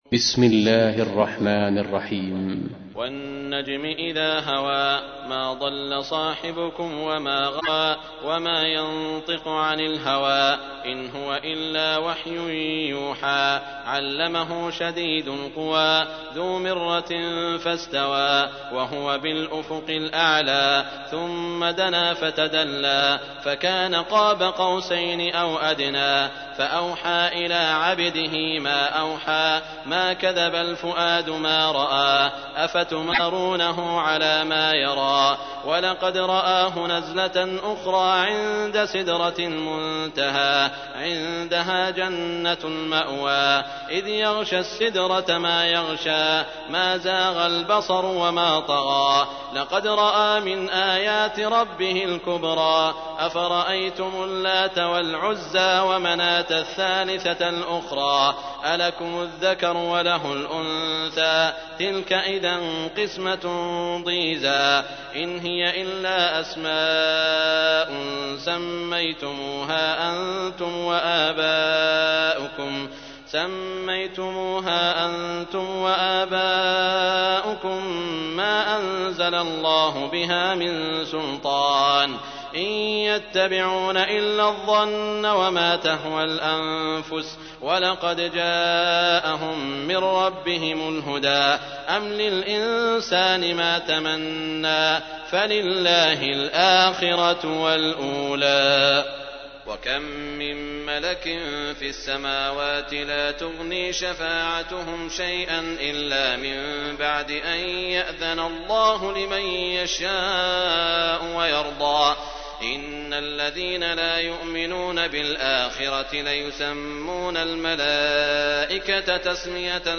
تحميل : 53. سورة النجم / القارئ سعود الشريم / القرآن الكريم / موقع يا حسين